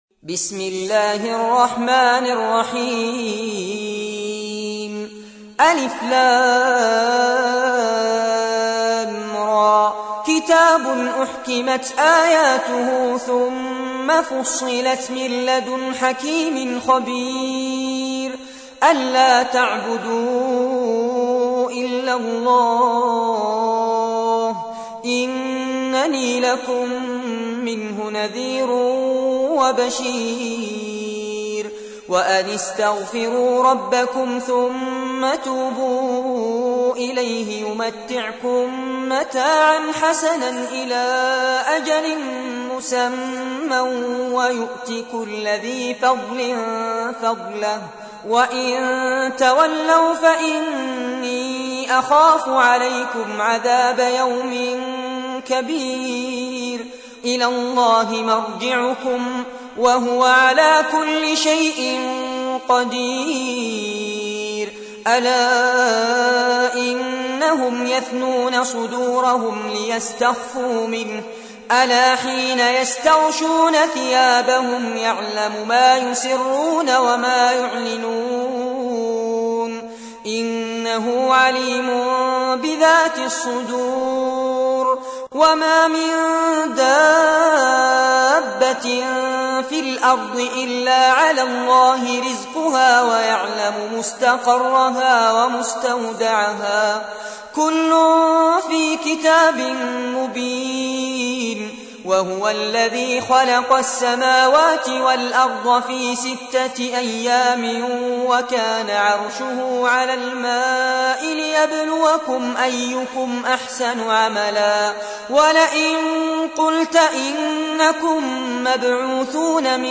تحميل : 11. سورة هود / القارئ فارس عباد / القرآن الكريم / موقع يا حسين